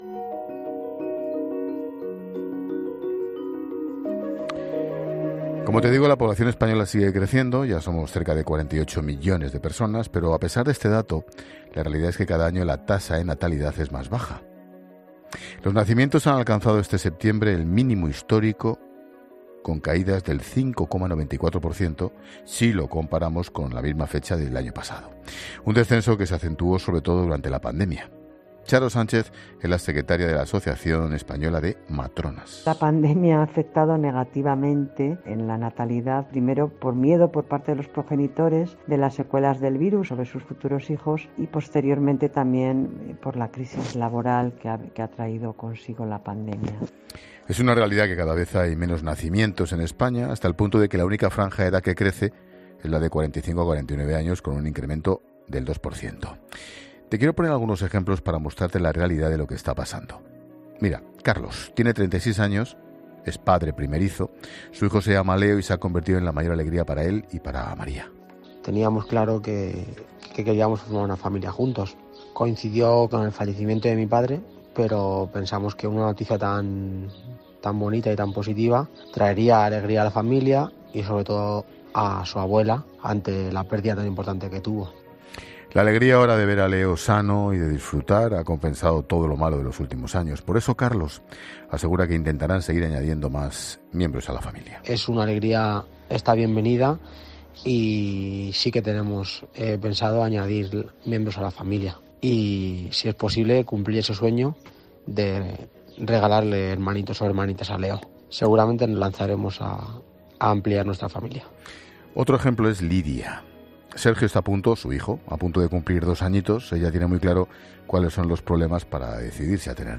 Para analizar la situación demográfica que tenemos en España se ha pasado por 'La Linterna'